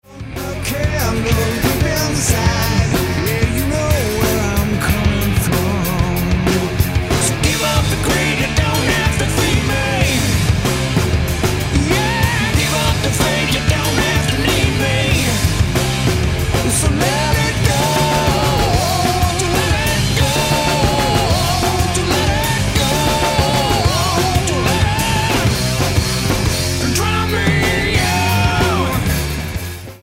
voc, gtr
drums
bass